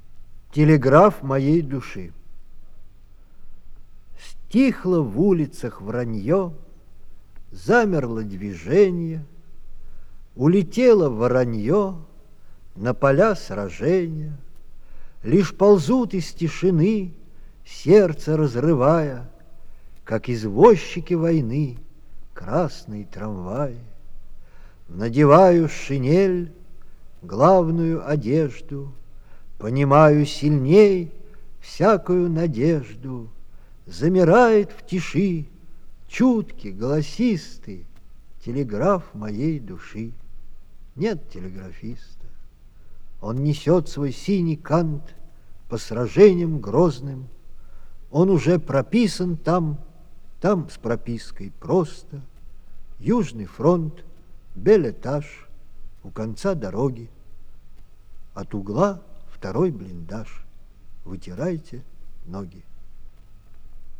Многие песни уже звучали на Завалинке,но здесь подборка песен и стихов на одном диске в исполнении автора.